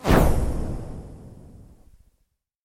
Звуки пуф
Шепот исчезновения